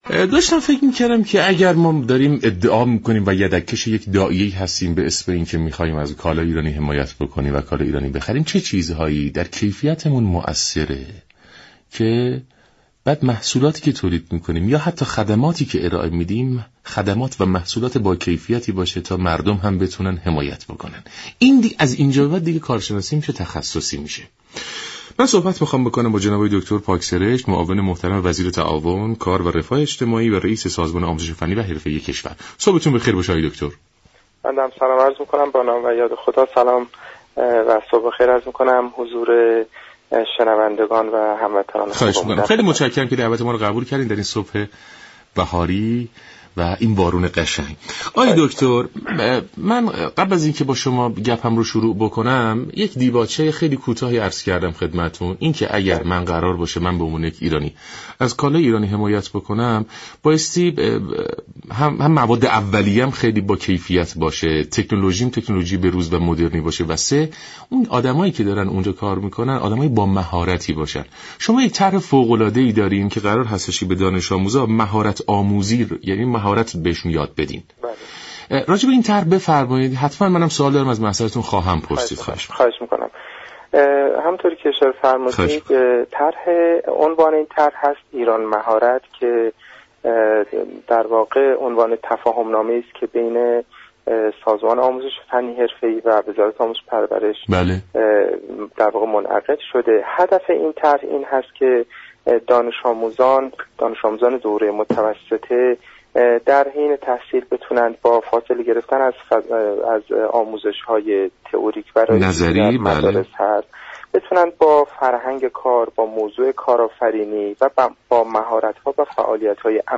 معاون وزیر تعاون، كار و رفاه اجتماعی در گفت و گو با سلام ایران گفت